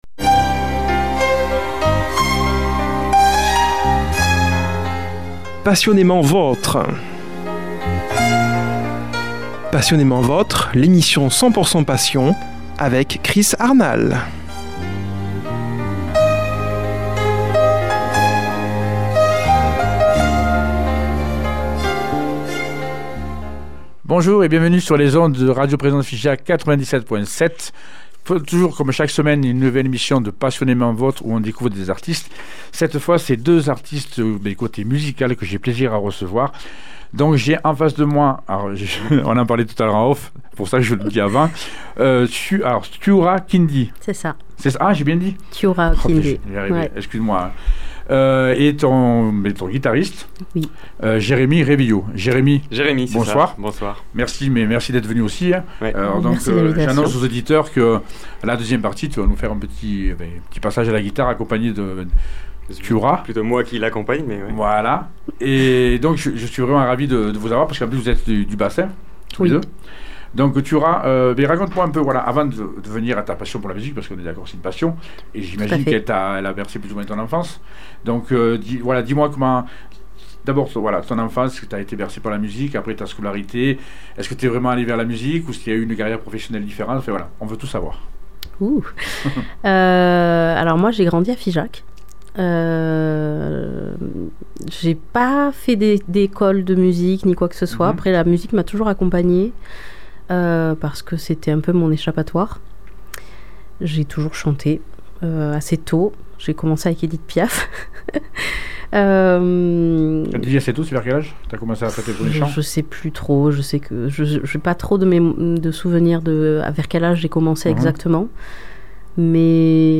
Un moment magique et hors du temps